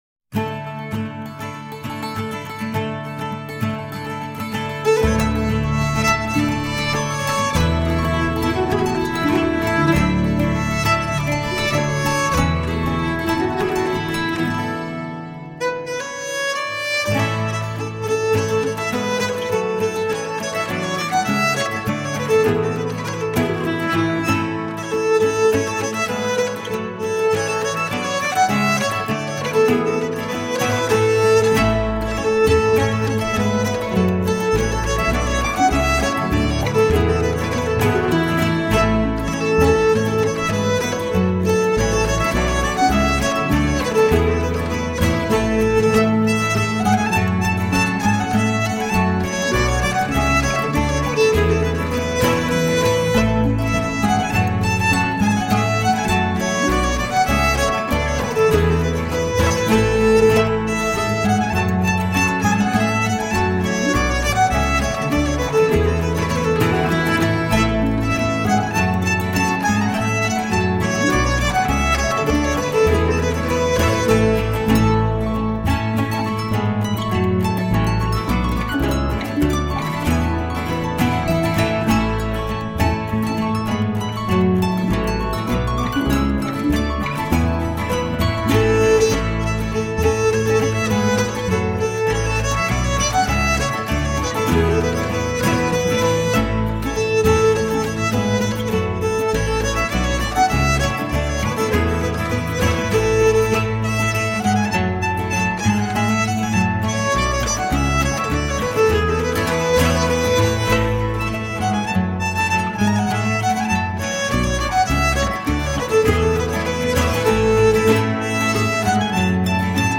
Timeless and enchanting folk music for the soul.
Uptempo, joyous dance music.
Tagged as: World, Folk, Celtic, Harp